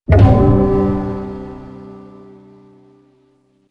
gamequit.wav
乐器类/重大事件短旋律－宏大/gamequit.wav
• 声道 立體聲 (2ch)